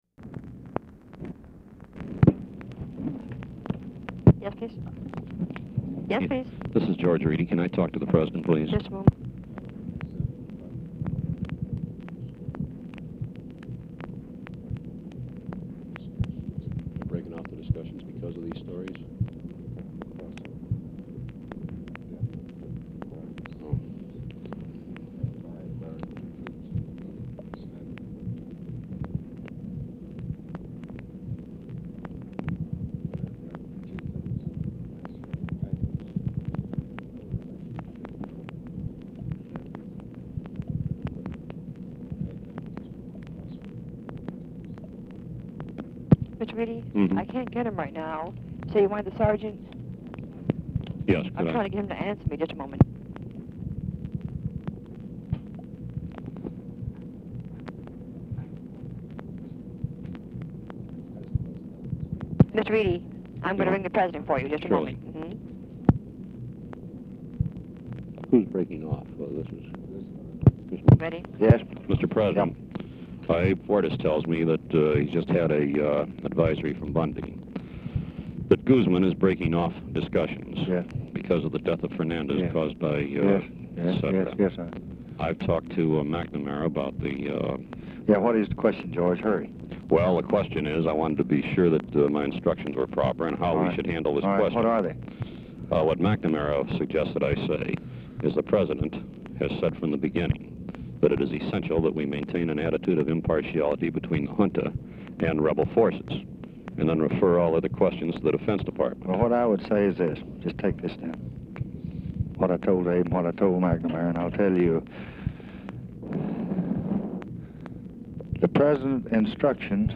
Telephone conversation # 7954, sound recording, GEORGE REEDY and LBJ, 5/20/1965, 11:25AM | Discover LBJ
Format Dictation belt
Other Speaker(s) TELEPHONE OPERATOR, OFFICE CONVERSATION
Specific Item Type Telephone conversation